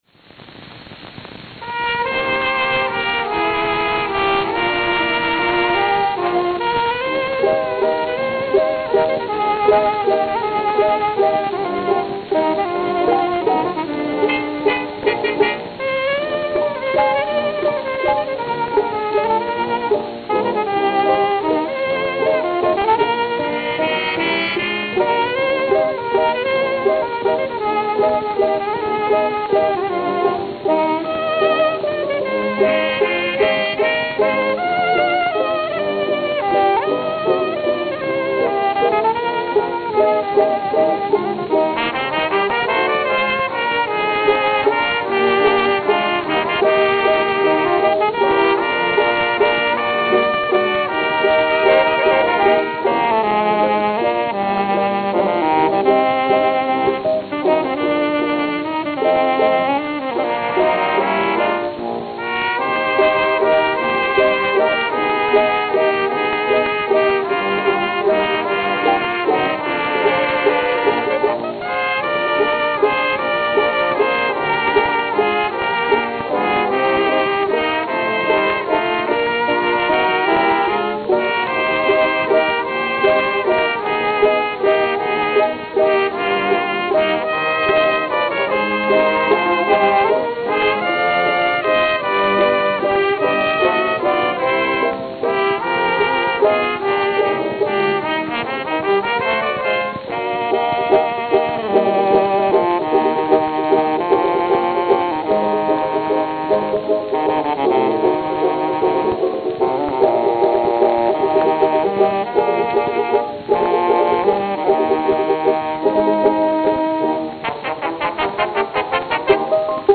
Note: Played at 78 RPM.